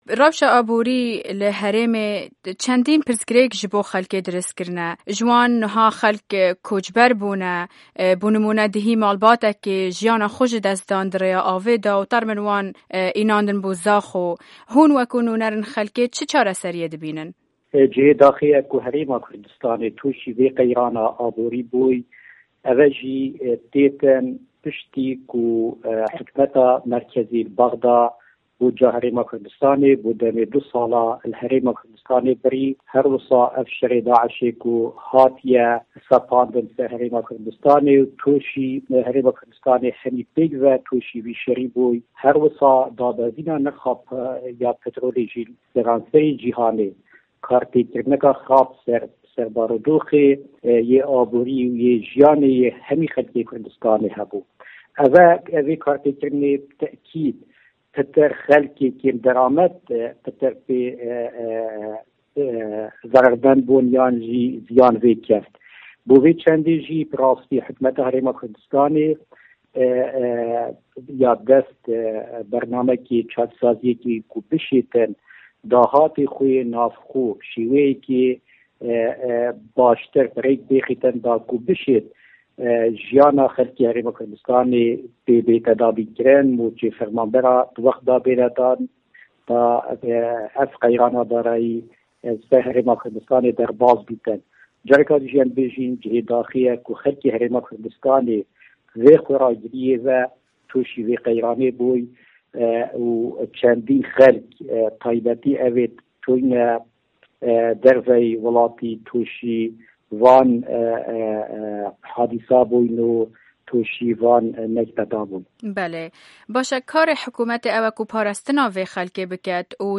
Hevpeyvin digel Beyar Tahir